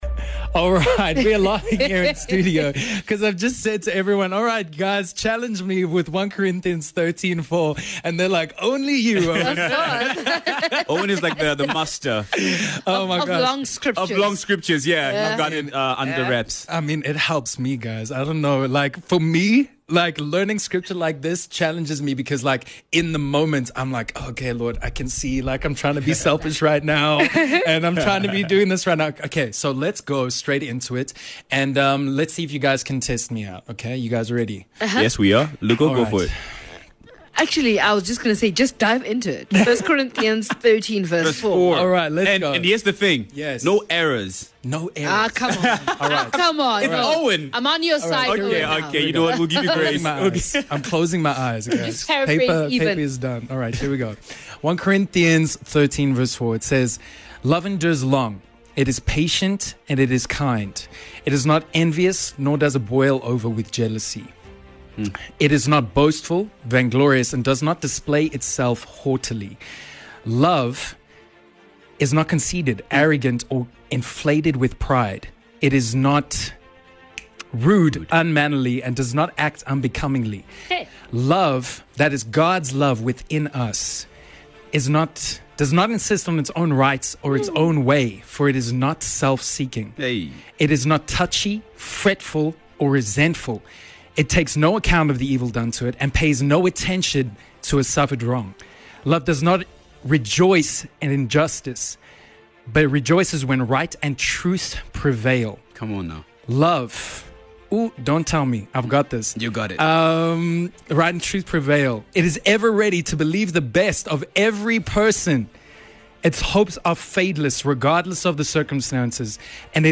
Filled with laughs, testimonies the Word of God.